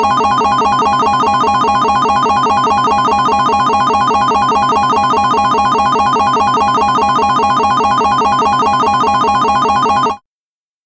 Slot Machine Payout Alarm